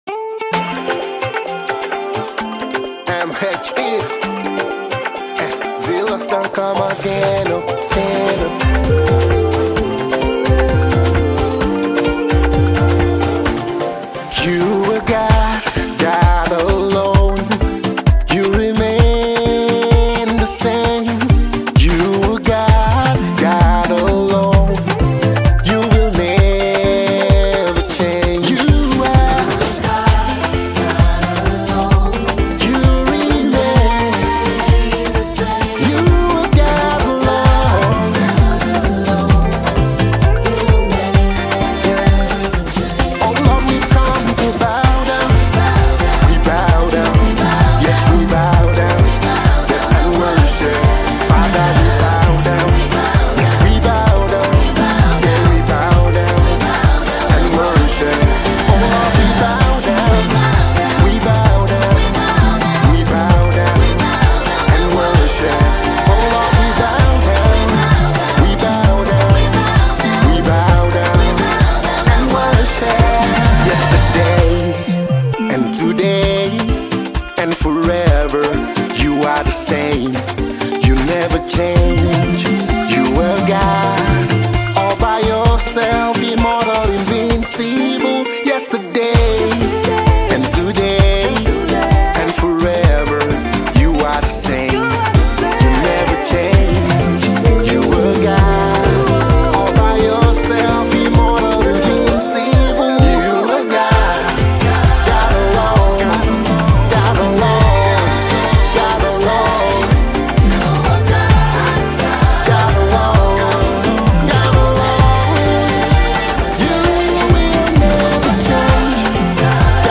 US-Based gospel artist
dance-ready new single